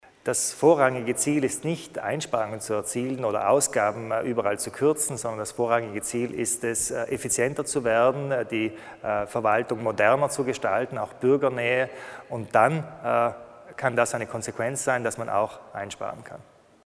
Landeshauptmann Kompatscher erläutert die Ziele des Treffens mit den Sozialpartnern